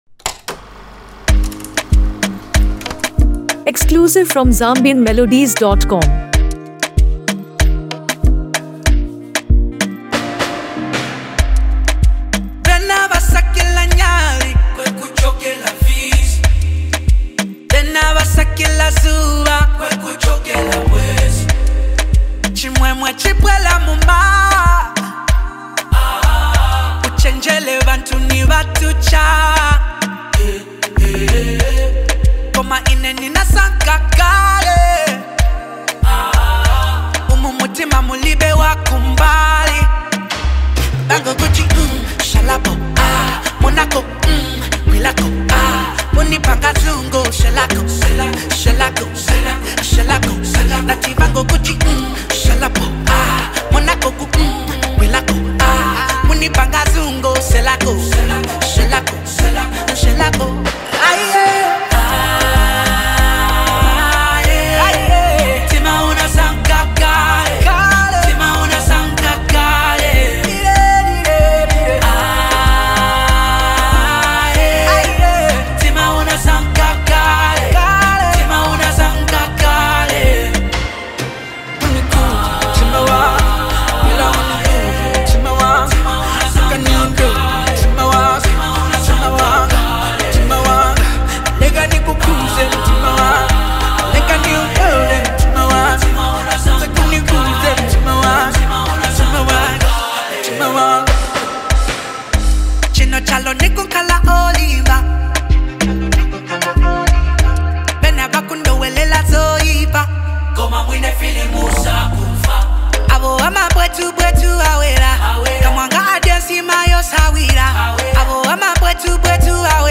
Known for his unique blend of Afro-soul and R&B
smooth vocals
combined with melodic instrumentation
modern Afro-soul rhythms